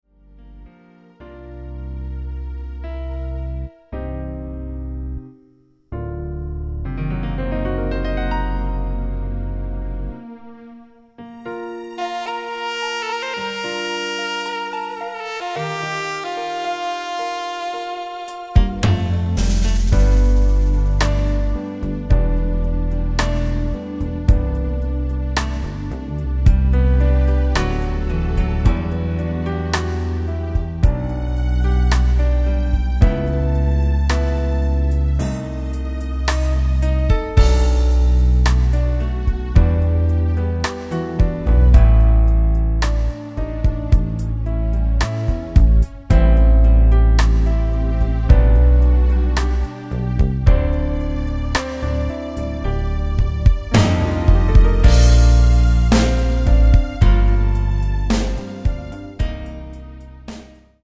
Full instrumentation.
Sax solo included.
Great for vocalists.
Key of B flat